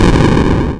ship_destroyed.wav